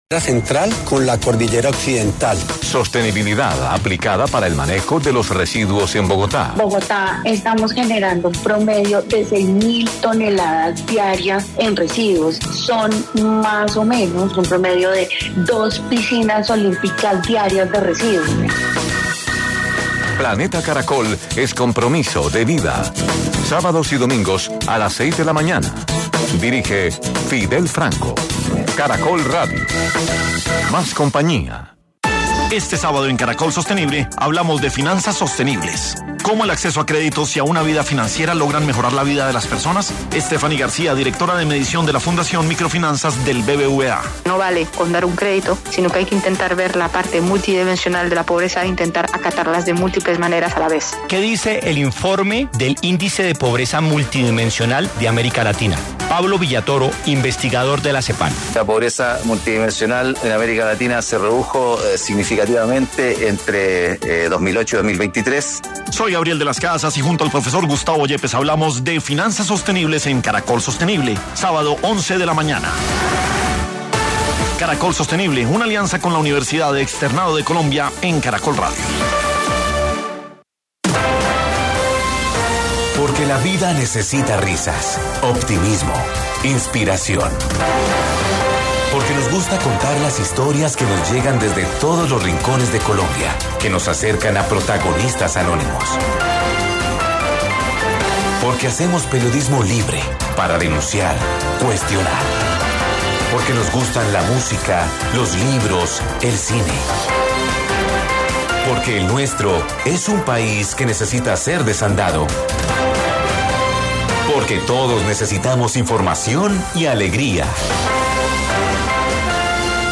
En 10AM de Caracol Radio estuvo Rafaela Cortés, gobernadora del Meta, quién manifestó su preocupación por el paro arrocero y las consecuencias que ha traído.